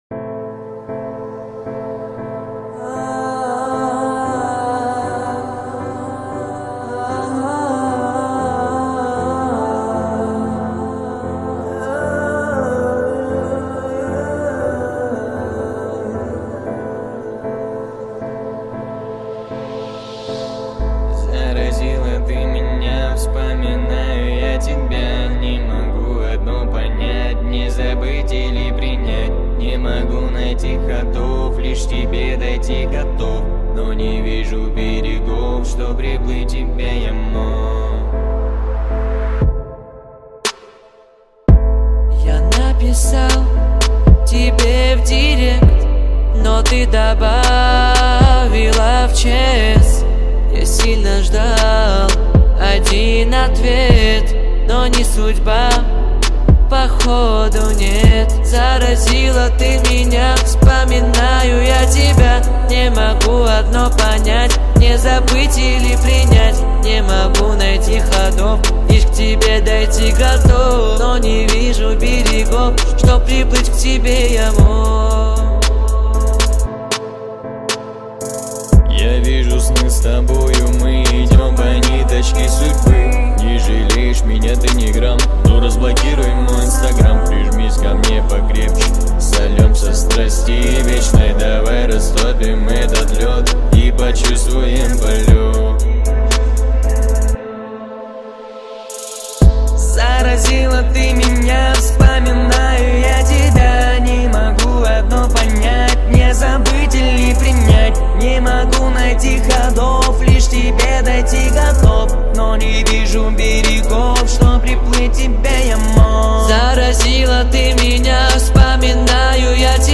Жанр: Русская музыка